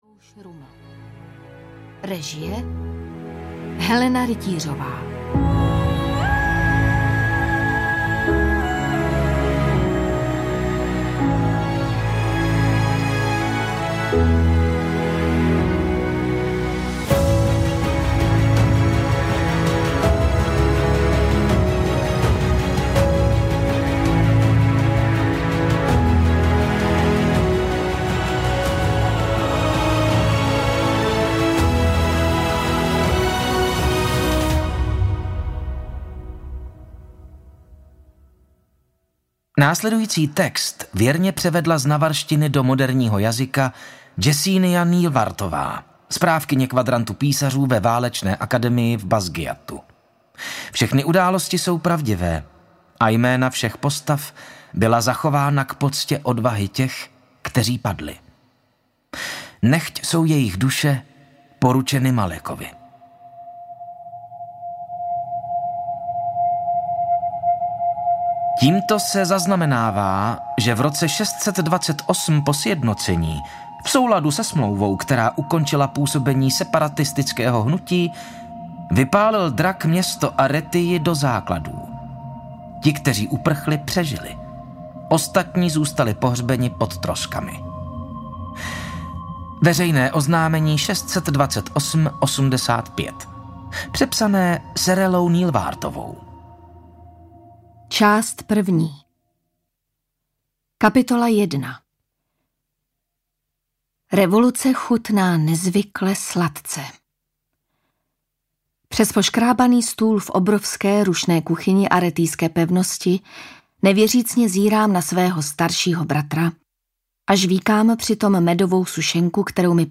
Železný plamen audiokniha
Ukázka z knihy